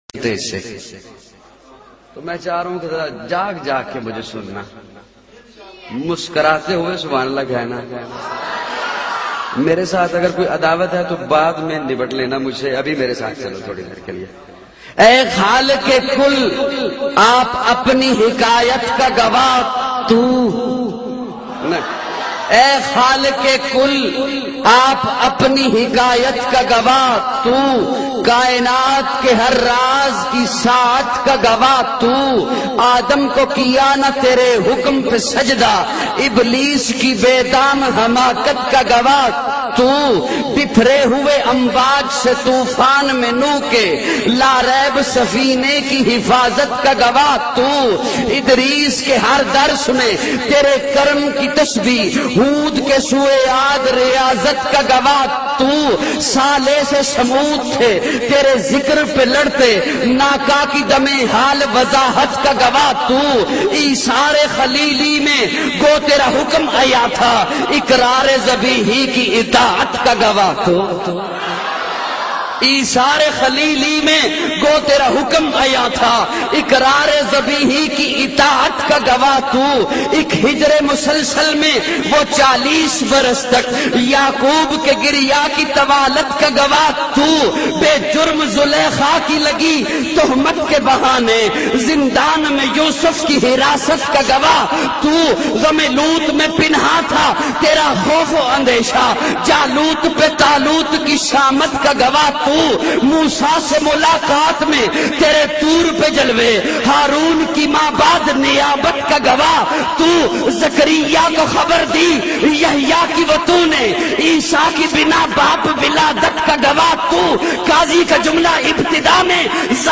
Allah Ki Shan bayan mp3